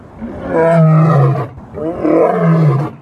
Singa_Suara.ogg